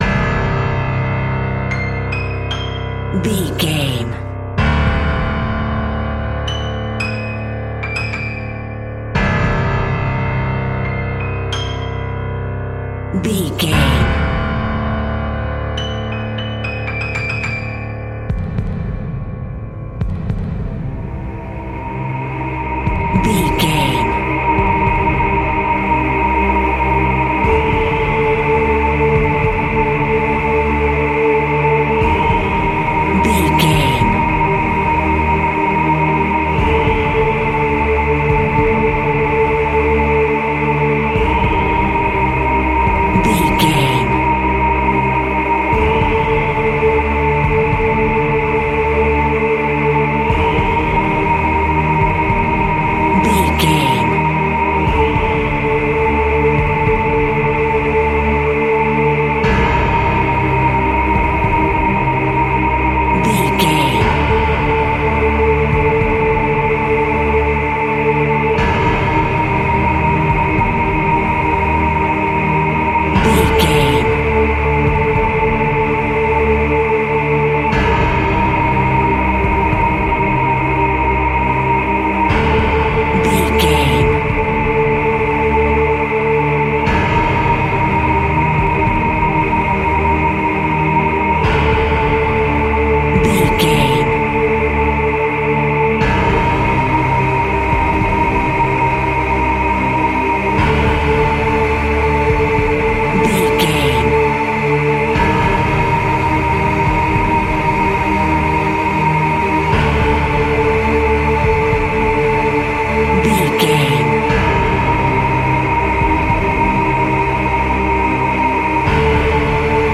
Aeolian/Minor
Slow
tension
ominous
haunting
eerie
piano
synthesiser
percussion